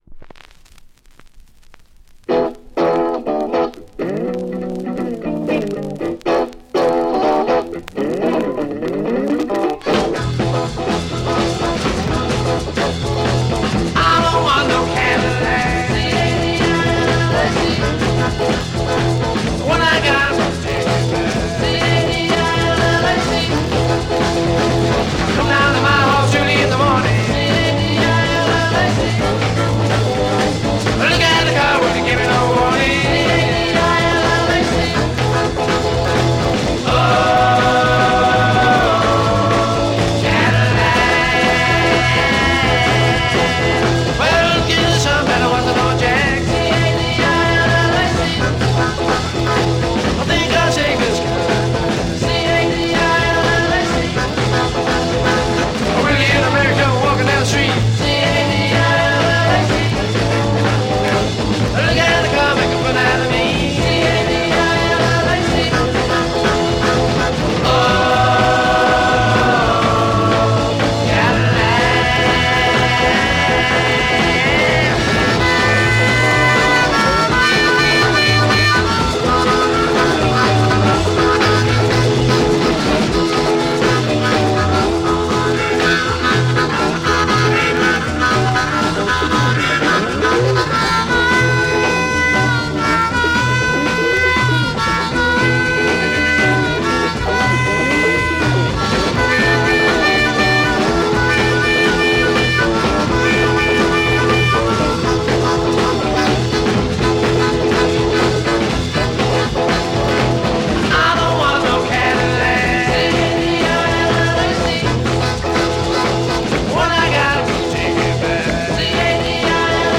Classic UK Freakbeat garage, rare French EP issue.